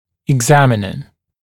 [ɪg’zæmɪnə] [eg-][иг’зэминэ] [эг-]исследователь; врач, проводящий осмотр